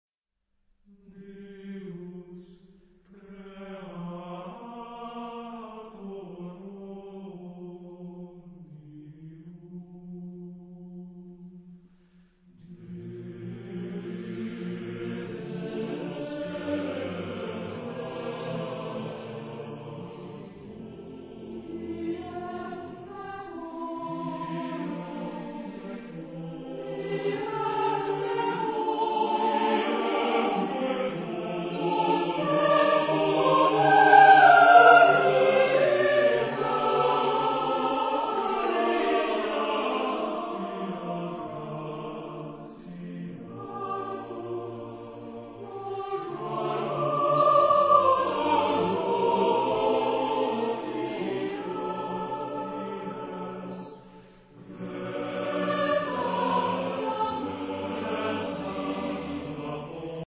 Género/Estilo/Forma: Sagrado ; Motete
Carácter de la pieza : calma
Tipo de formación coral: SATB (div)  (4 voces Coro mixto )
Tonalidad : libre